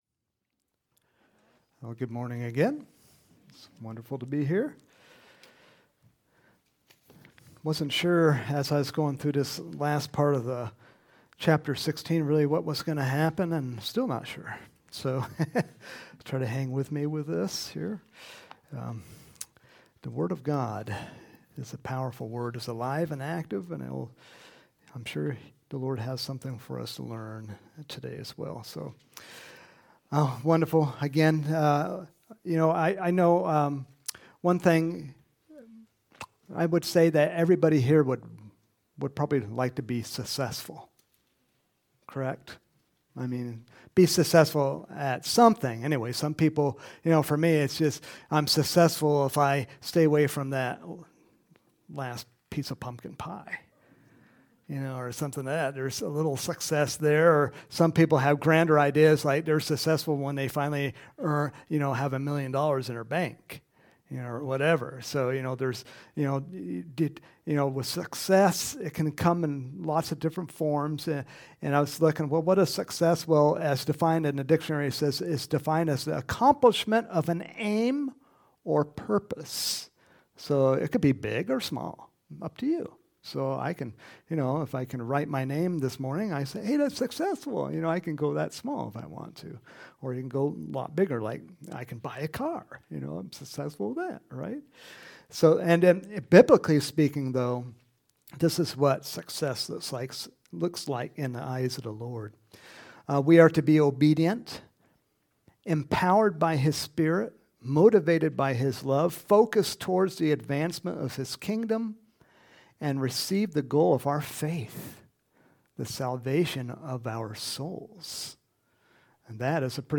Home › Sermons › April 14, 2024